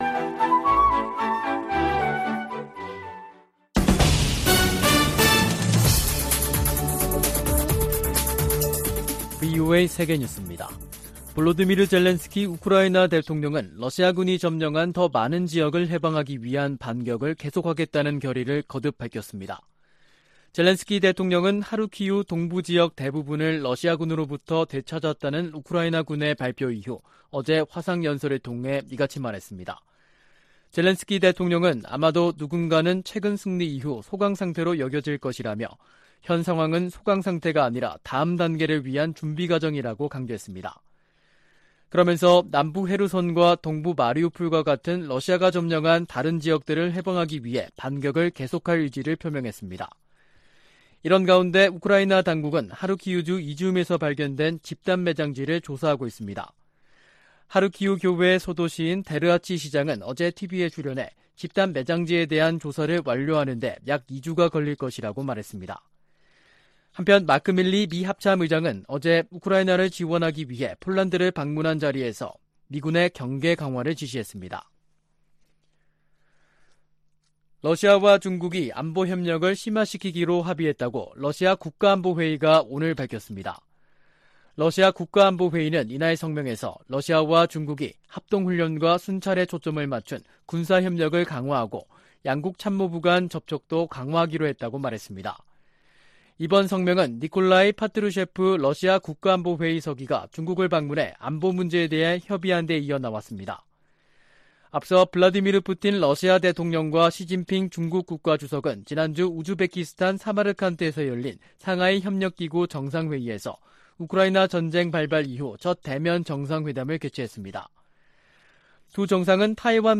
VOA 한국어 간판 뉴스 프로그램 '뉴스 투데이', 2022년 9월 19일 2부 방송입니다. 미국은 대북 억제를 위해 전략자산의 효과적인 역내 전개와 운용이 지속되도록 한국과의 공조 강화를 약속했습니다. 미 해군은 로널드 레이건 항공모함이 부산에 입항해 한국군과 연합훈련할 계획이라고 밝혔습니다. 제77차 유엔총회에서 미국은 식량 안보와 보건 협력, 안보리 개혁 문제를 주요 우선순위로 다룹니다.